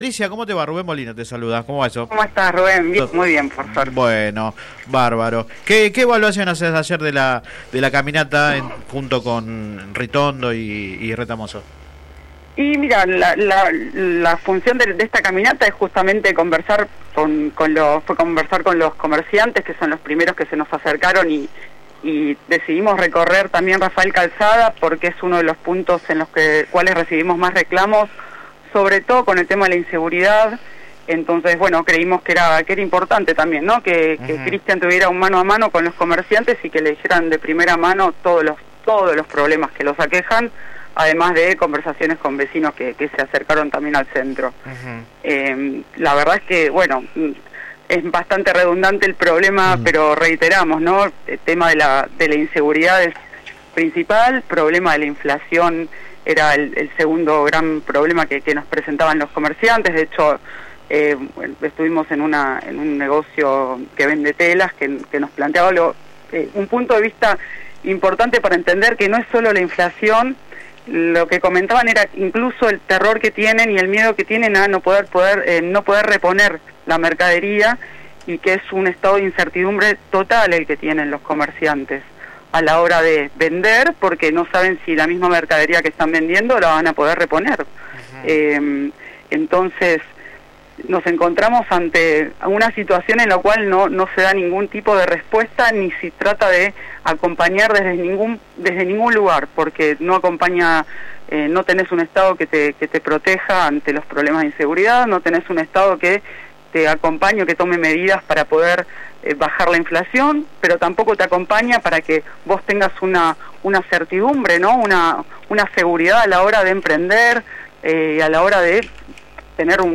La concejal por el PRO de Almirante Brown, Patricia Bontempo, habló en el programa radial Sin Retorno (lunes a viernes de 10 a 13 por GPS El Camino FM 90 .7 y AM 1260). Se refirió a la reciente visita de Cristian Ritondo a Rafael Calzada y el reclamo de los vecinos y comerciantes por mayor seguridad.
Click acá entrevista radial